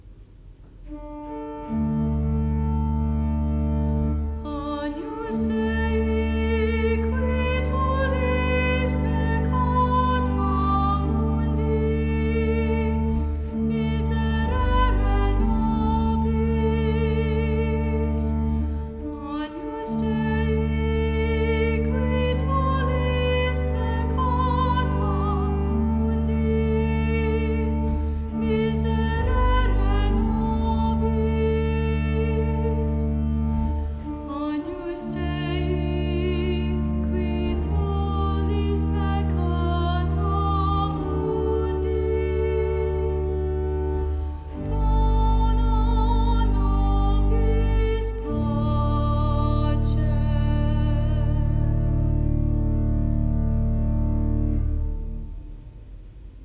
Solo (David Mass recordings)
solo-agnus-dei.m4a